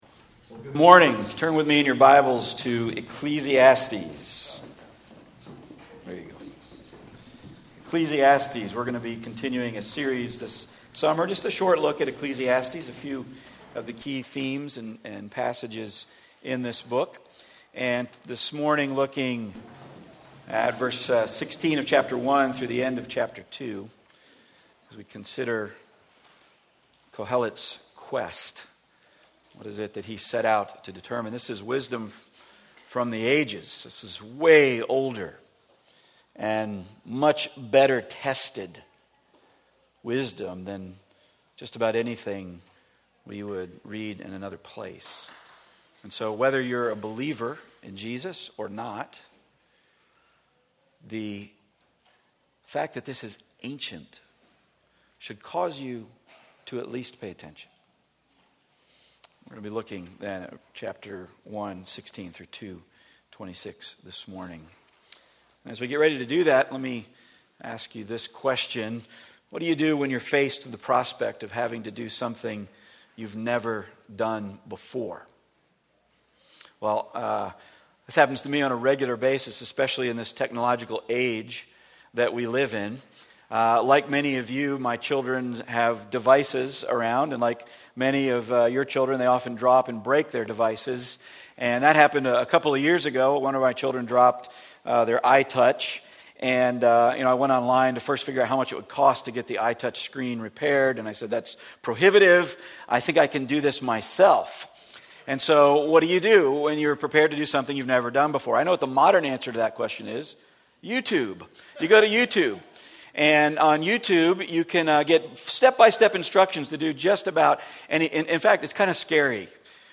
Ecclesiastes 1:16-2:26 Service Type: Weekly Sunday Part 2 of a Series on the book of Ecclesiastes « Vanity